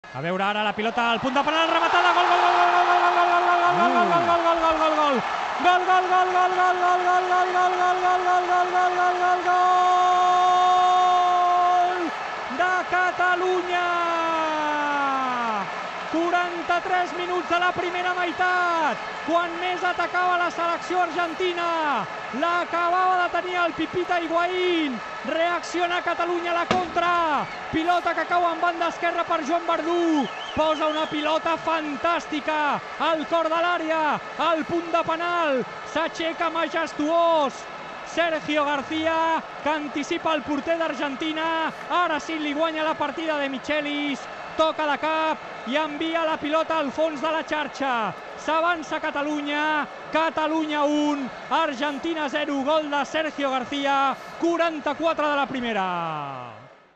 Narració dels gols del partit entre les seleccions de Catalunya i Argentina
Esportiu